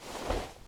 Backpack Sounds
action_open_inventory_0.ogg